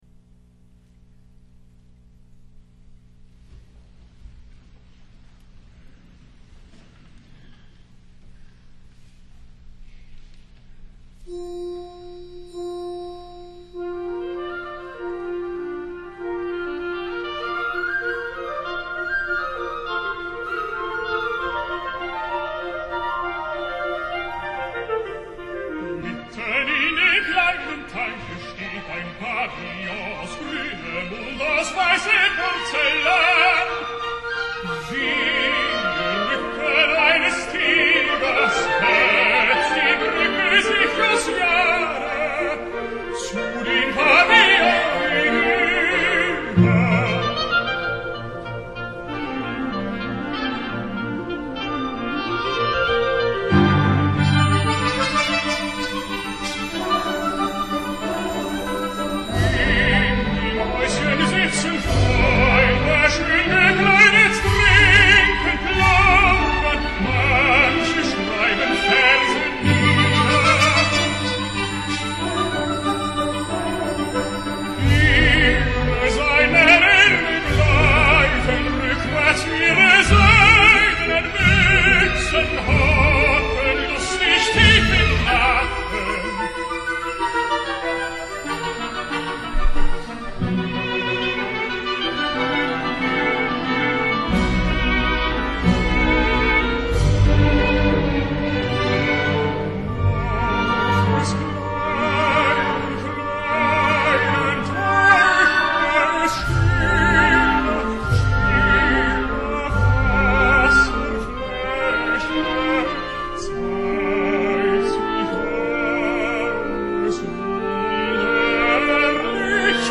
La interpretació que us porto avui i que com ja és habitual, us deixaré per si voleu baixar-vos-la, prové d’un concert celebrat quasi fa un any ( 9 i 10 de març de 2008 ) amb la fantàstica The Cleveland Orchestra sota la direcció del seu actual director titular, Franz Welser-Möst i el tenor alemany Jonas Kaufmann (1969) i el baríton anglès Christopher Maltman (1970).
La demostració de Kaufmann en els tres lieds (Das Trinlied von Jammer der Erde, Von der Jugend i Der Trunkene im Frühling) és extraordinària.